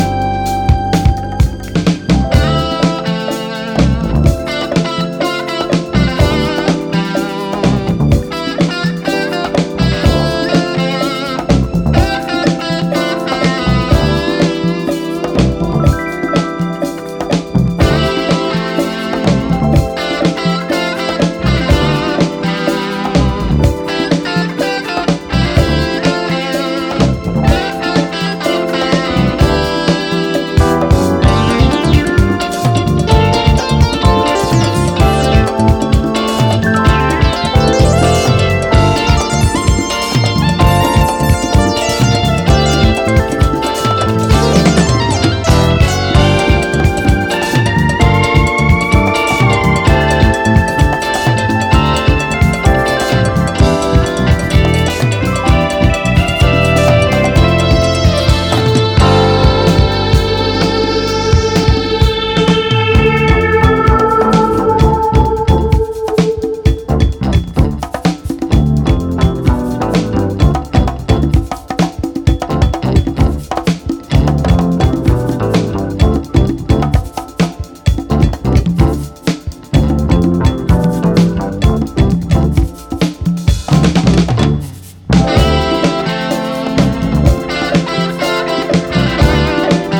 タイトでグルーヴィーなブギー・チューンから美しいハーモニーを聴かせるミッド〜スローまで充実の内容！